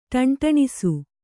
♪ ṭaṇṭaṇisu